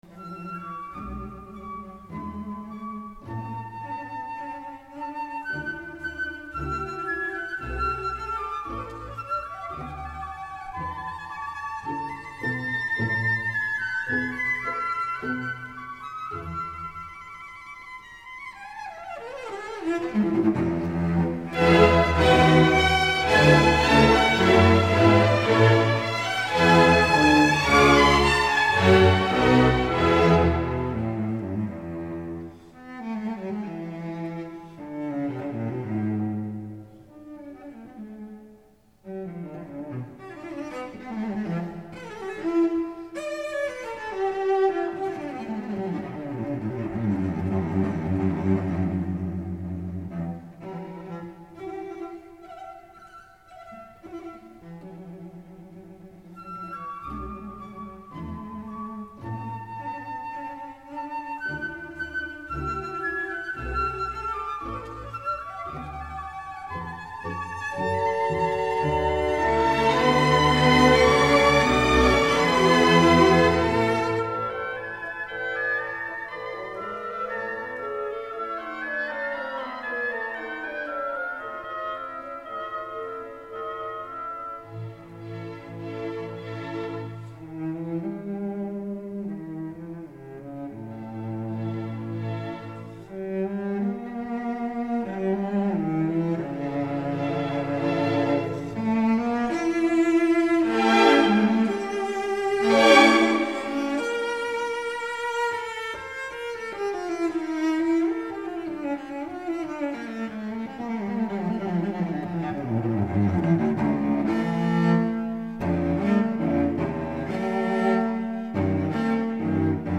ДЛЯ ВИОЛОНЧЕЛИ С ОРКЕСТРОМ